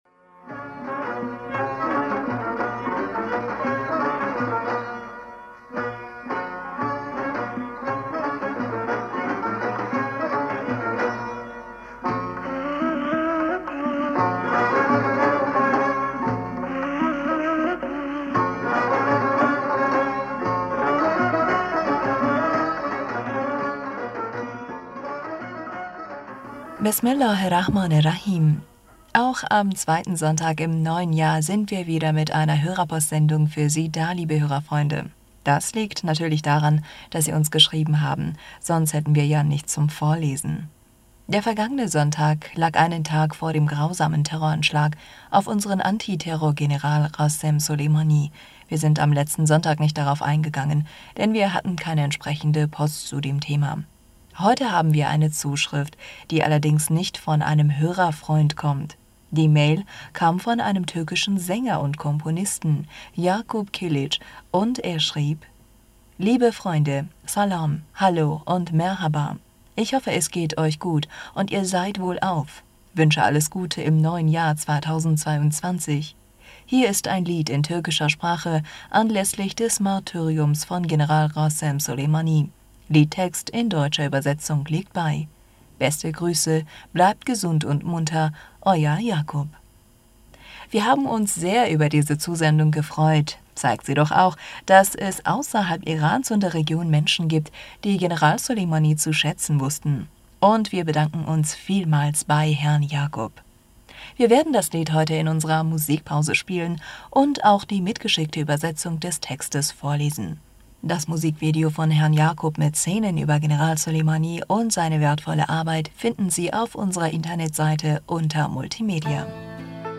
Hörerpostsendung am 9. Januar 2022
Wir werden das Lied heute in unserer Musikpause spielen und auch die mitgeschickte Übersetzung des Textes vorlesen.